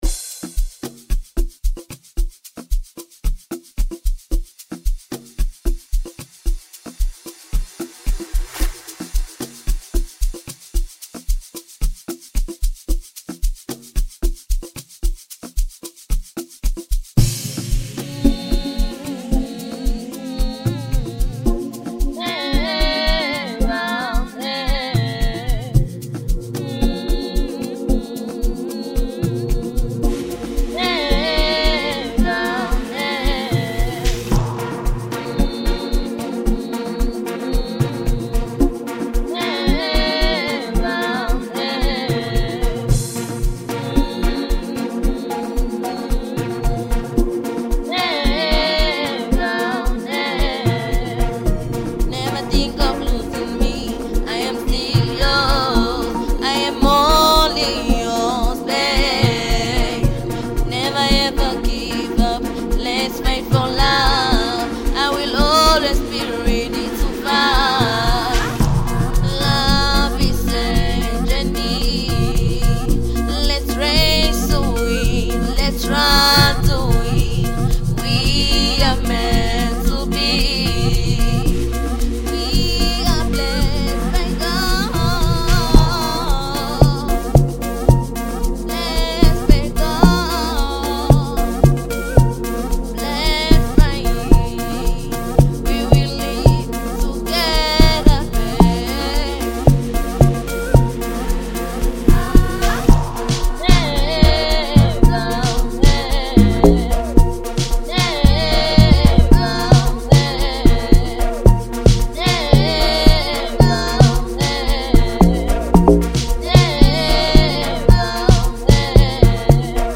05:32 Genre : Amapiano Size